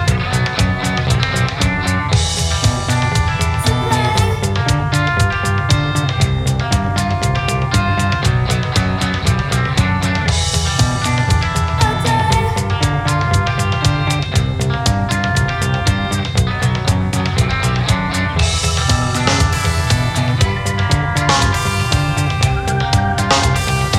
no Backing Vocals Punk 3:55 Buy £1.50